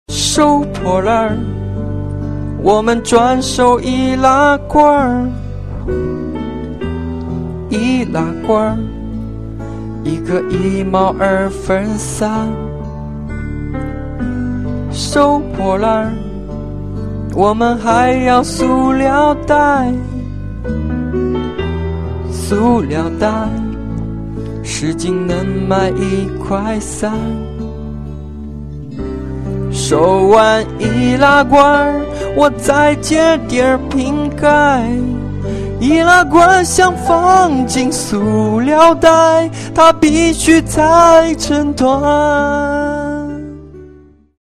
收破烂 易拉罐塑料袋 特效人声铃声二维码下载
收破烂 易拉罐塑料袋 特效人声手机铃声下载 请用微信/其他工具 扫描左侧的二维码 直接下载到电脑: 收破烂 易拉罐塑料袋 特效人声 (右键目标另存为) ↓ 将 收破烂 易拉罐塑料袋 特效人声 下载到手机操作步骤： 打开微信,右上角魔术棒->扫描二维码 弹出手机浏览器,选择下载 恭喜您把收破烂 易拉罐塑料袋 特效人声铃声下载到手机 点击在线试听铃声 收破烂 易拉罐塑料袋 特效人声 温馨提示： 这是收破烂 易拉罐塑料袋 特效人声手机铃声。